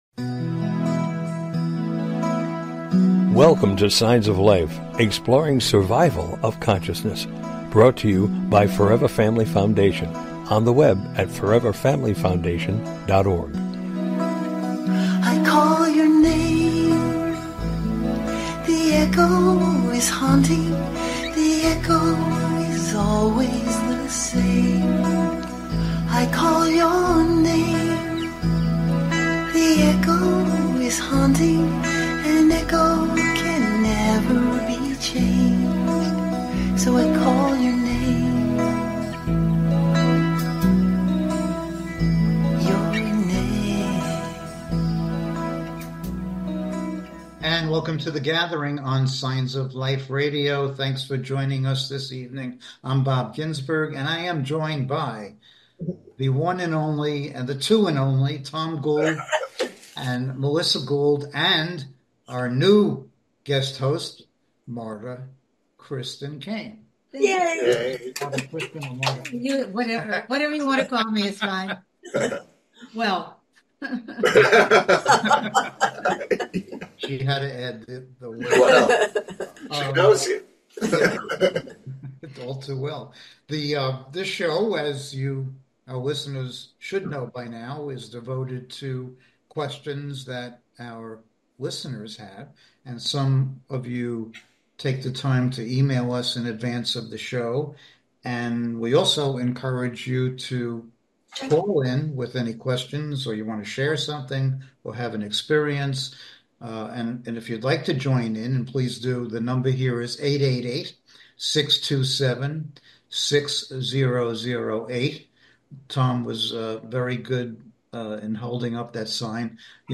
Join us this evening for an engaging live discussion on life after death and consciousness!
Tonight our panel will be addressing questions from our listeners that pertain to all matters related to life after death, consciousness, spirituality, etc.